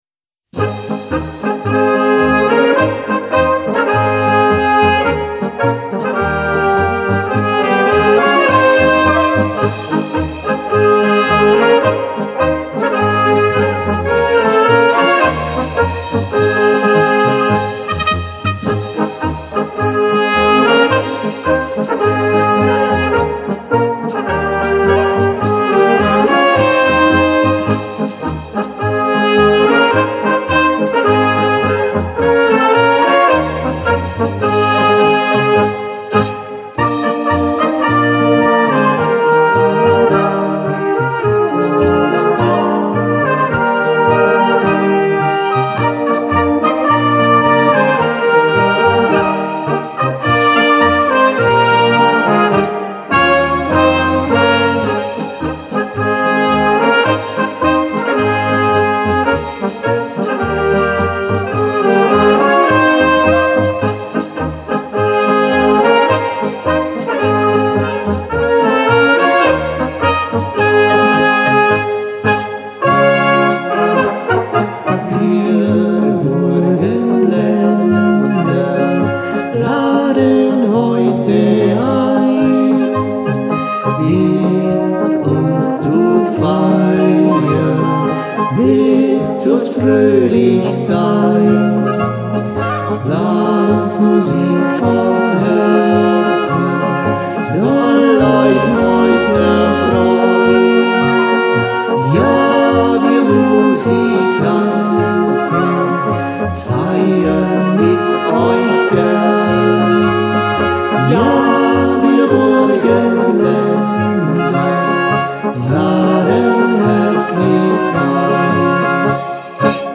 Blasorchester